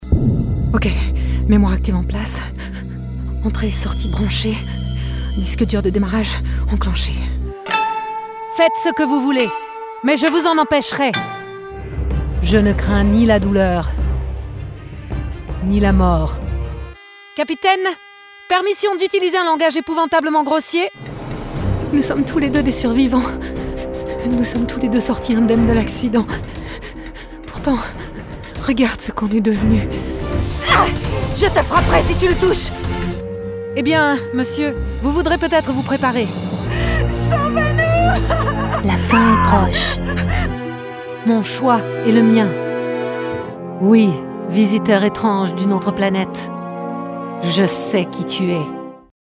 Natürlich, Vielseitig, Freundlich, Warm, Corporate
Persönlichkeiten
She owns a professional recording studio with Source Connect Standard.